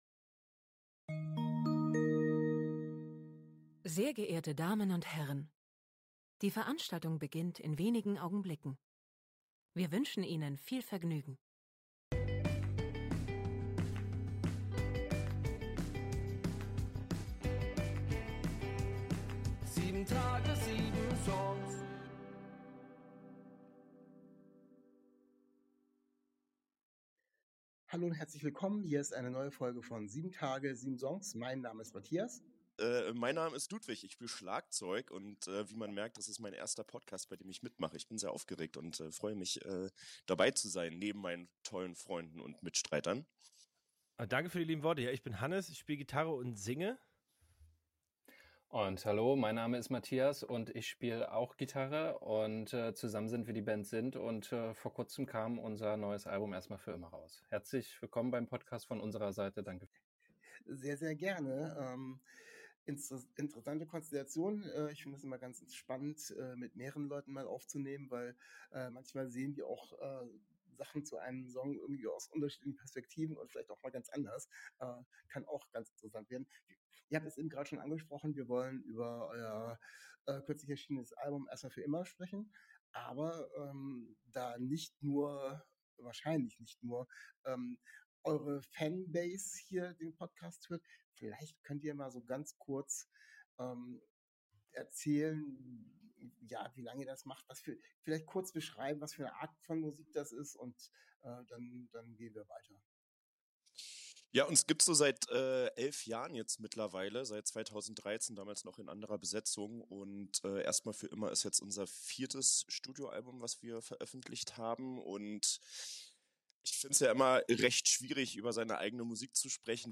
Letzte Episode #4.40 Zu Gast: SIND 21. Dezember 2024 Nächste Episode download Beschreibung Kapitel Teilen Abonnieren Zu Gast ist die Band SIND. Die drei Mitglieder der Band stellen ihre aktuelle LP vor.